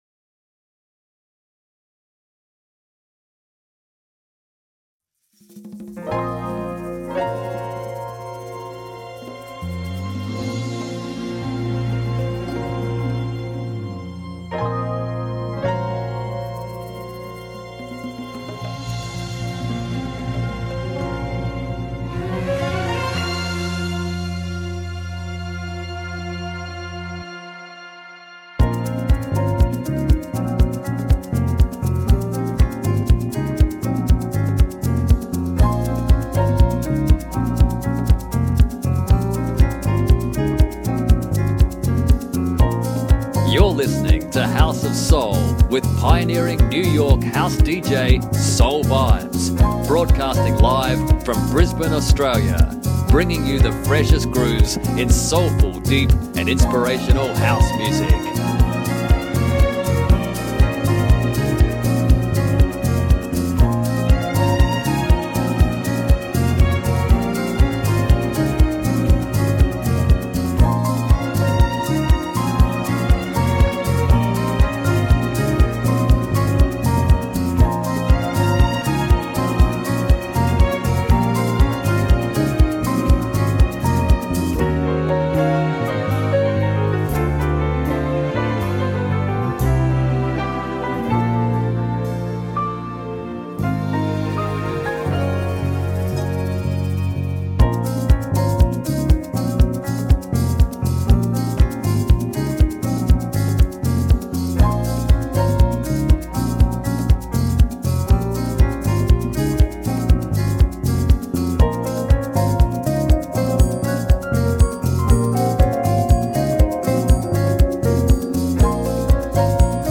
Mixed Live
mix show